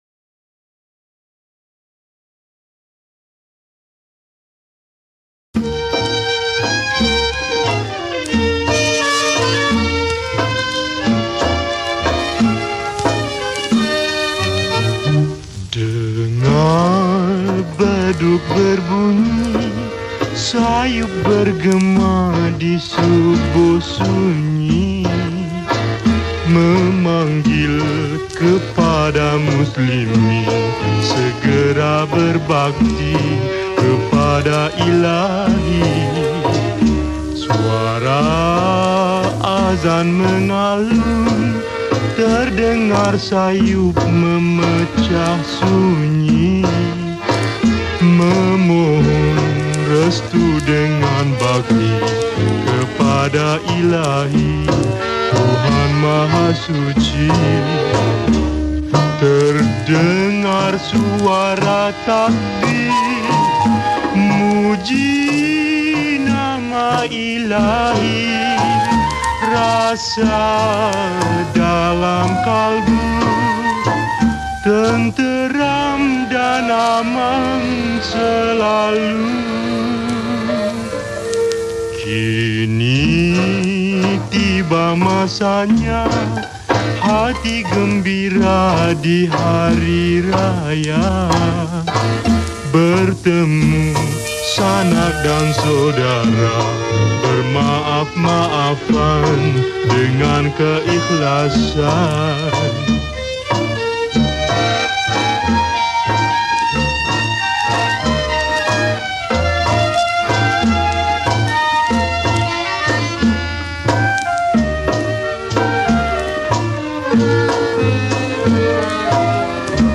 Lagu Hari Raya , Malay Songs